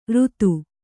♪ řtu